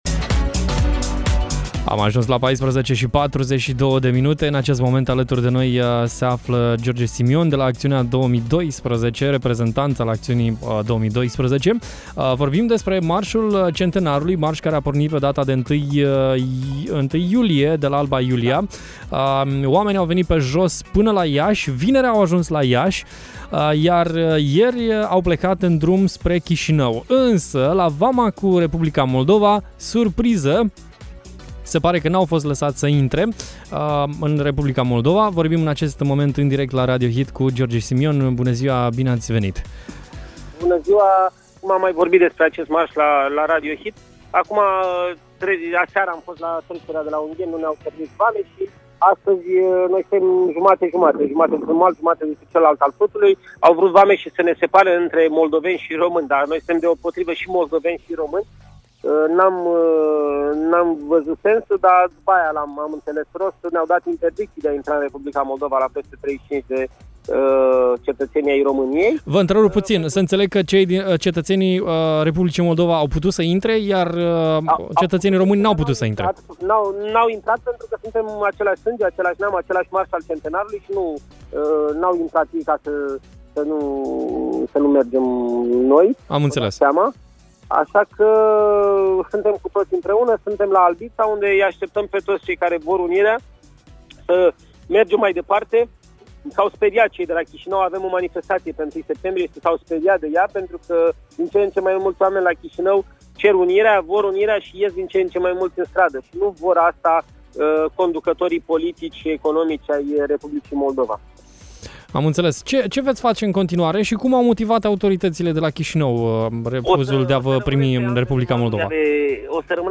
Dupa ce a străbătut toată România, Marșul Centenarului a fost oprit la granița cu Republica Moldova. Mai multe detalii am aflat în direct la Radio Hit de la George Simion, organizator al marșului și reprezentant al organizației Acțiunea 2012: